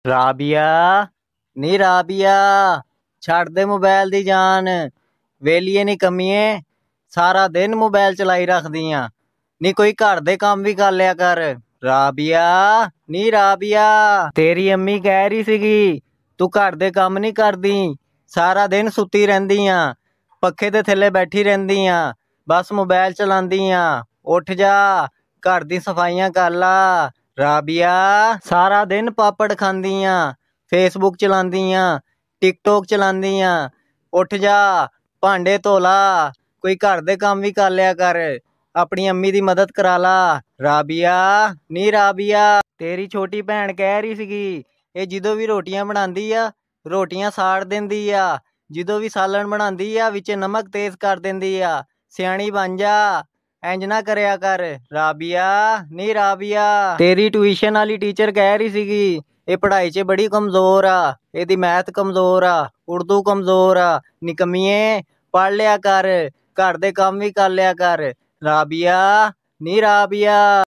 Goat calling RABIA in funny sound effects free download
Goat calling RABIA in funny and amazing style very funny goat